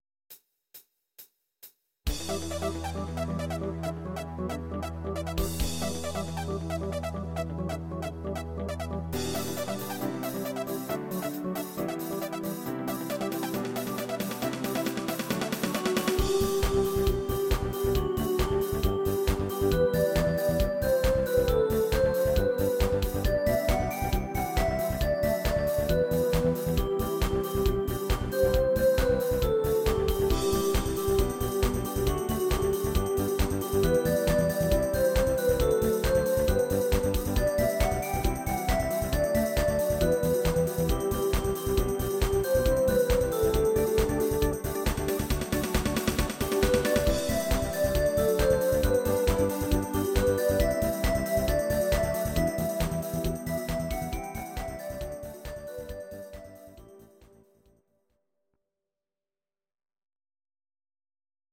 Audio Recordings based on Midi-files
Pop, 2000s